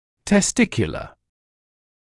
[tes’tɪkjulə][тэс’тикйулэ]тестикулярный, относящийся к семенникам